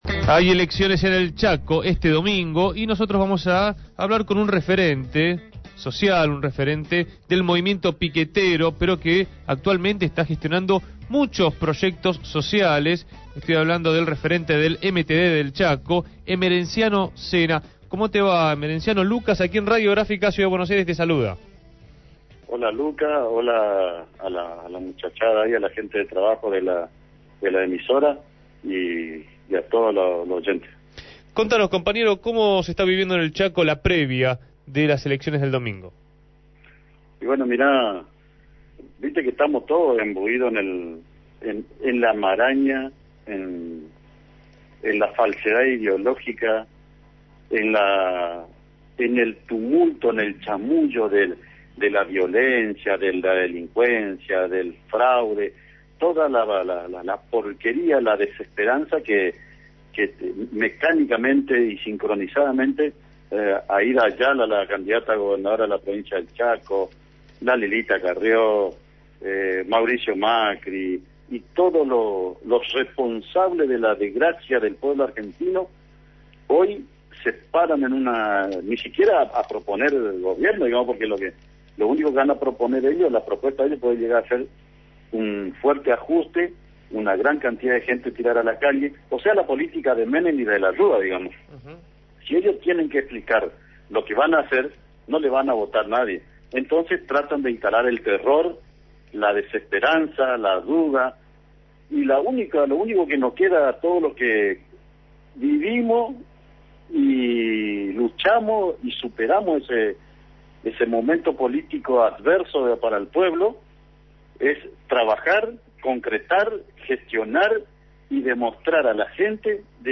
dialogó en Punto de Partida sobre la situación en Chaco y aseguró que la oposición quiere instalar la desesperanza.